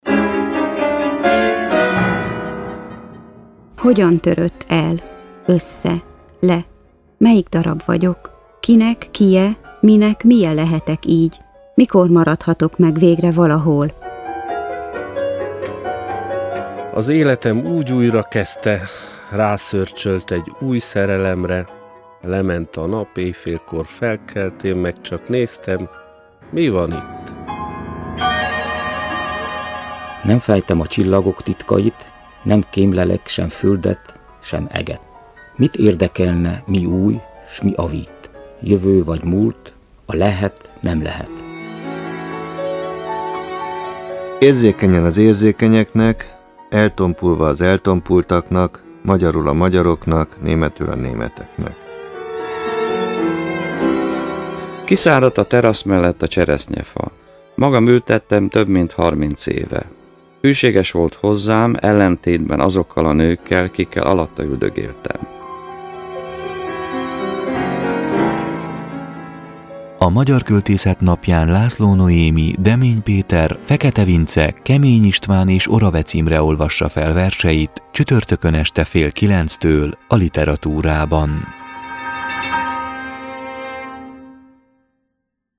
PROMO-Magyar-kolteszet-napja-2019.mp3